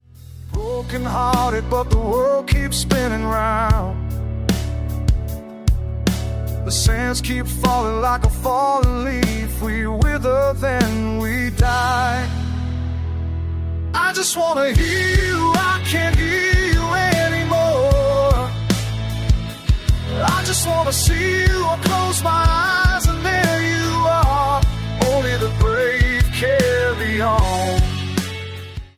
song recorded in the UK and then Bangtao, Phuket
was studio recorded in the UK in 2011
Genre: Slow Rock.